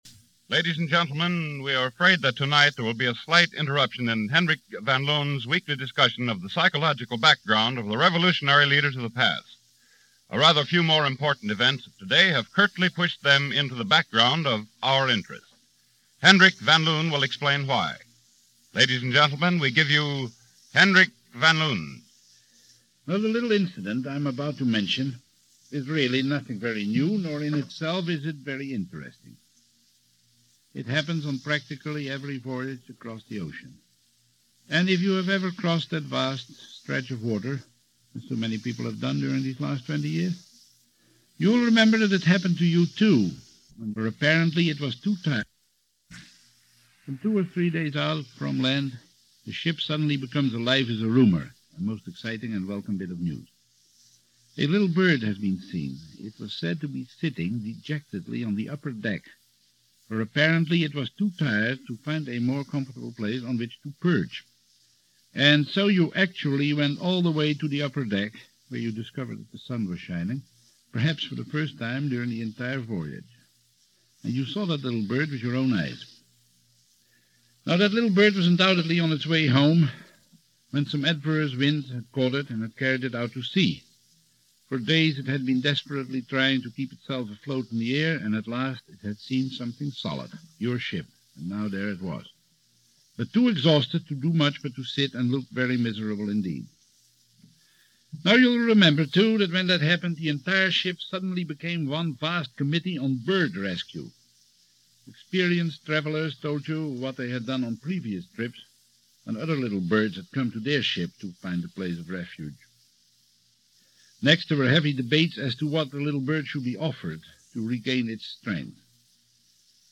About Guernica - The Spanish Civil War - May 26, 1937 - Past Daily After Hours Reference Room - Henrik Willen van Loon Commentary